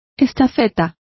Complete with pronunciation of the translation of courier.